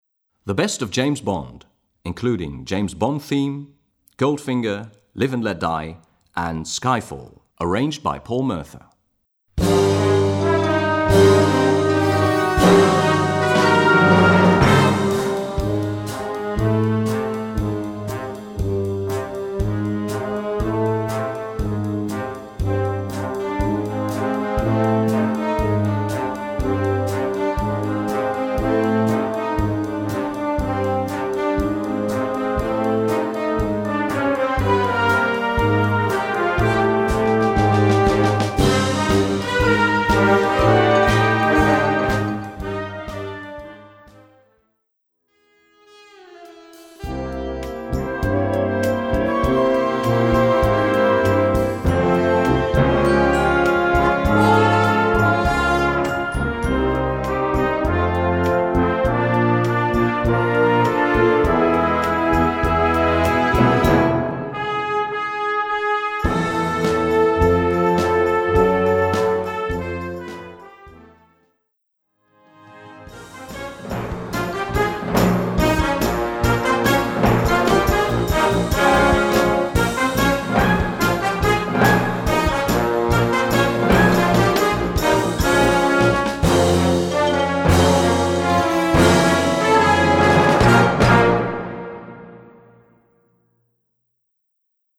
Gattung: Medley für Jugendblasorchester
Besetzung: Blasorchester